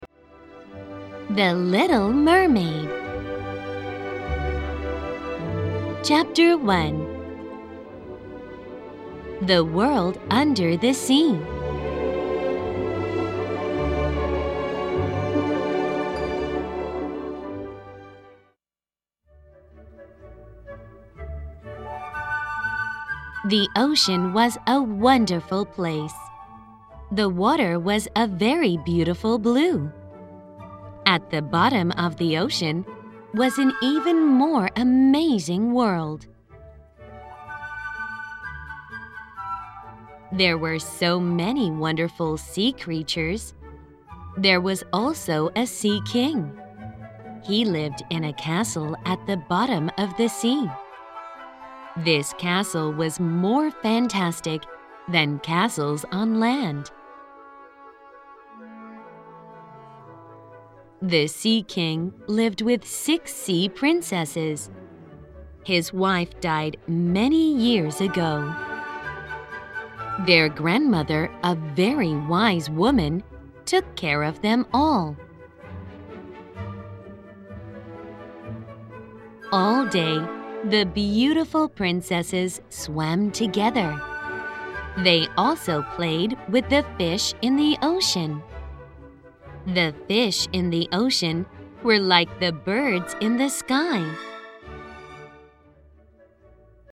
每冊書均錄製有MP3，由專業英語老師朗誦故事全文，幫助從內文與習題中練習聽力和口語能力。
本書生動活潑的朗讀音檔，是由專業的美國播音員所錄製；故事是由以英文為母語的專業編輯，參照教育部公布的英文字彙改寫而成，對於所有學生將大有助益。